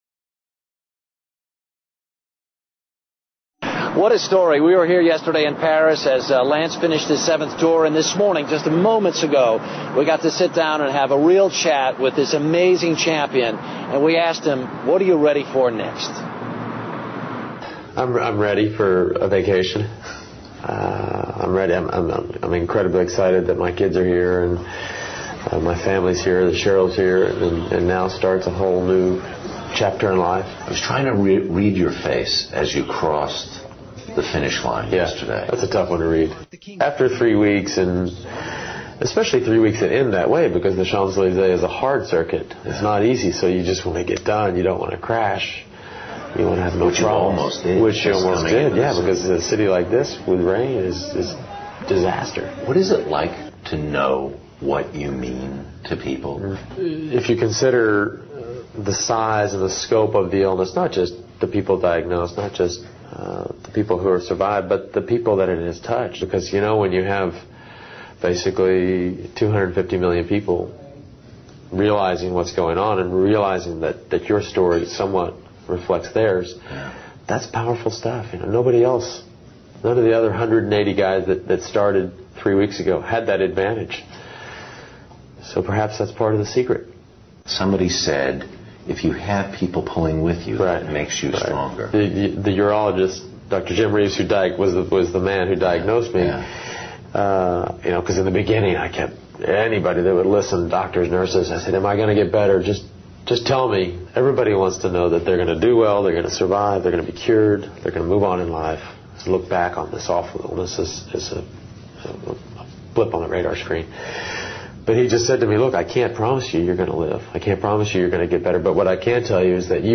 环法七连冠阿姆斯特朗专访 听力文件下载—在线英语听力室